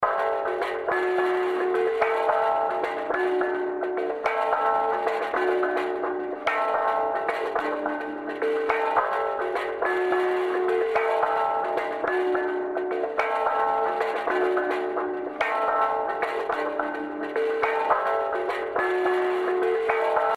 The break is a Balinese percussion loop found on the Jerry Marotta website (no, he didn’t play with me, sigh!). In this song it plays faster than the original, here it is:
Marotta-percussion-break.mp3
I consider it a gem within the song, for the superposition of odd rhythms and careful design of panning.
Jerry Marotta : Balinese percussion